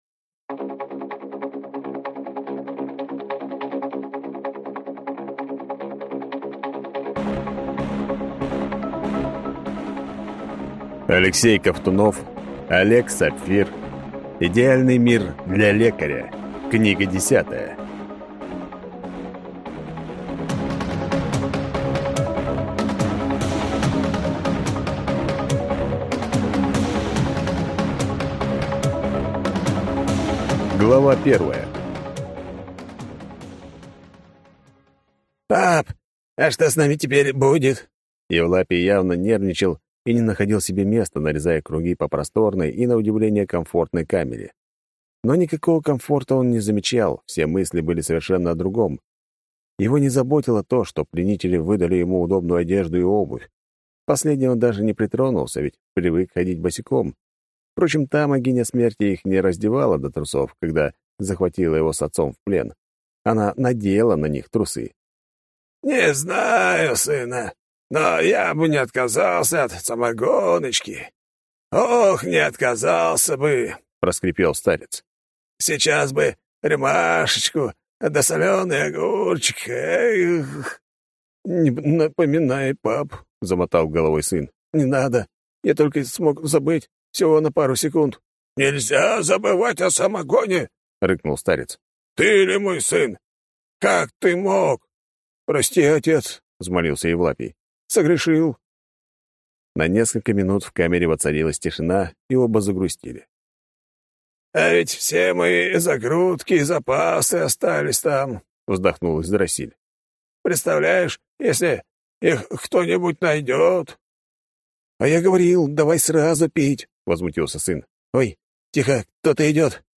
БЕГИ к себе. Как начать бегать и получать удовольствие (слушать аудиокнигу бесплатно) - автор Светлана Сирота